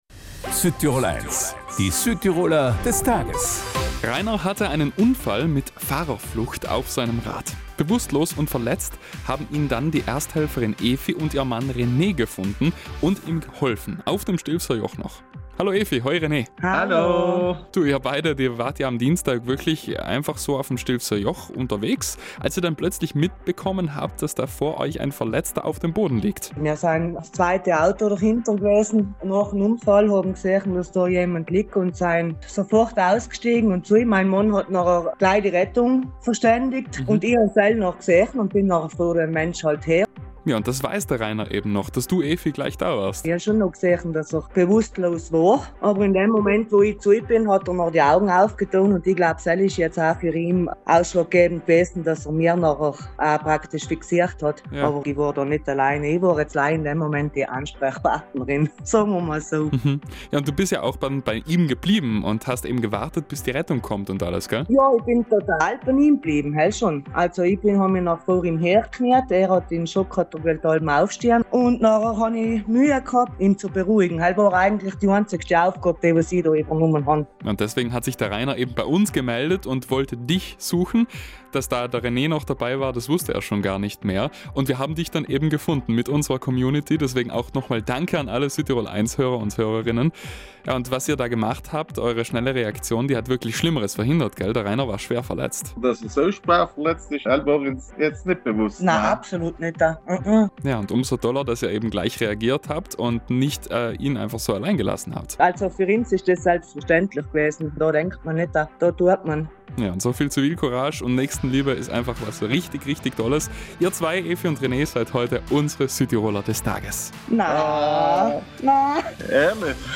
Hier finden Sie das Interview mit den Beiden